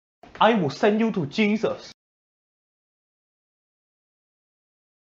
I Will Send You To Jesus Meme Sound Effect